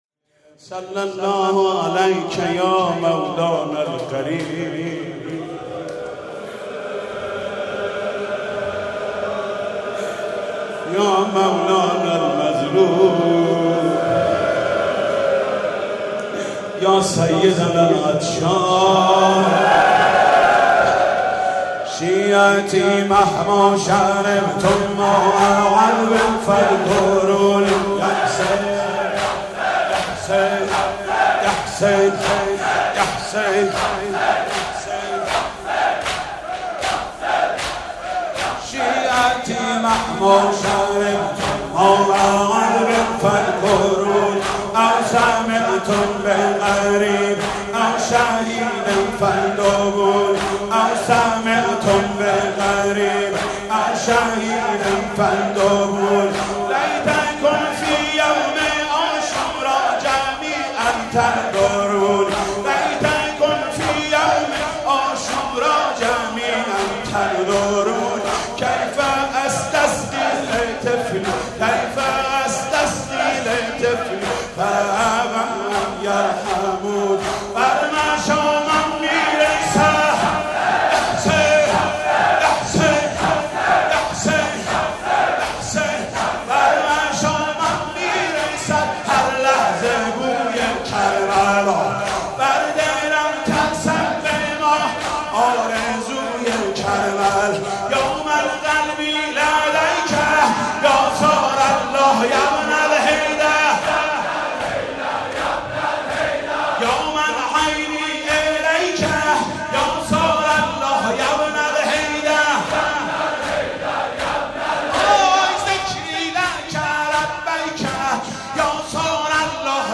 مناسبت : شهادت امام علی‌النقی الهادی علیه‌السلام
مداح : محمود کریمی قالب : واحد